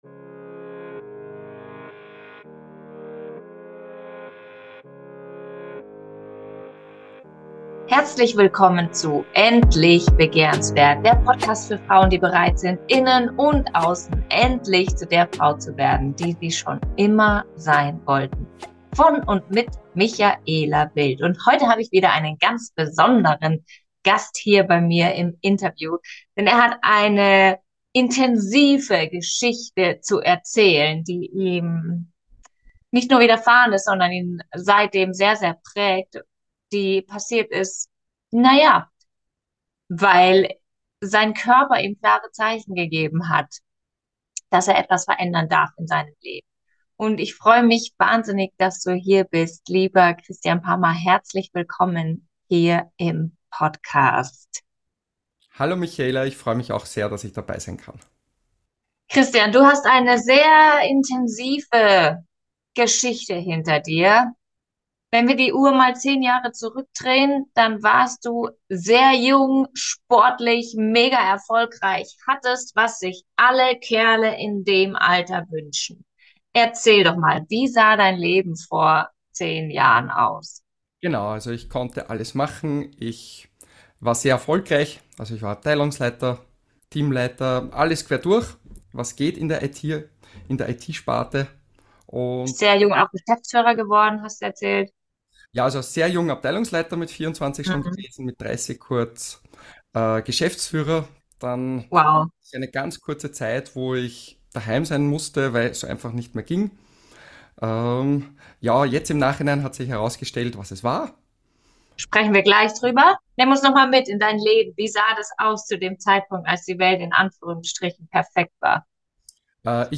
Wie er das geschafft hat, verrät er uns in diesem Interview.